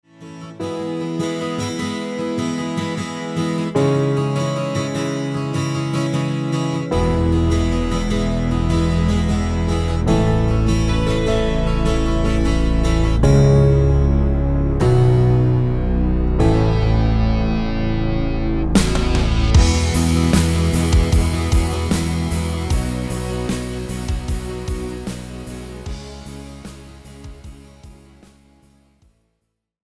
Žánr: Pop
BPM: 151
Key: E
MP3 ukázka